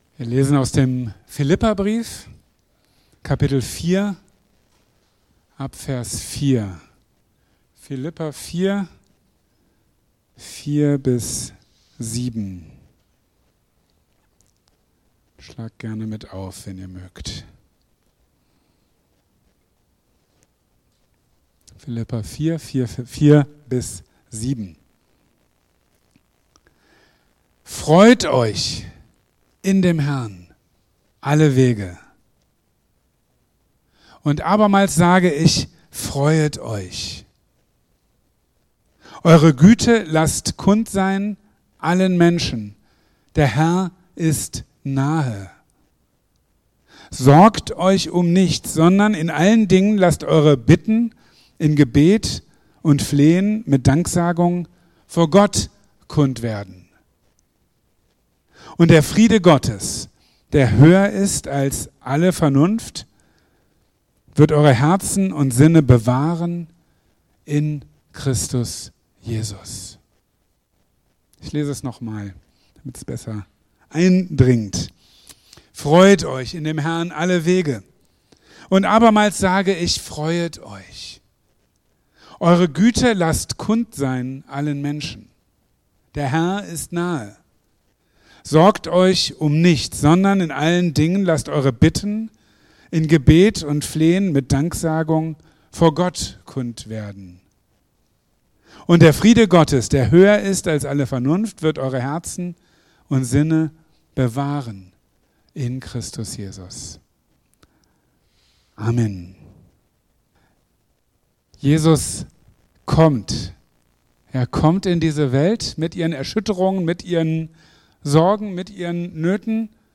| Marburger Predigten